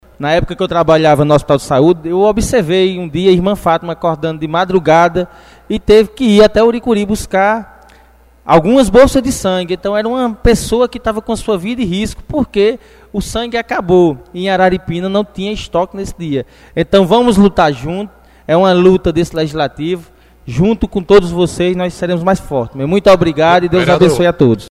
Vereador João Erlan apresentou Requerimento na noite desta quarta-feira (27), na Câmara Legislativa de Araripina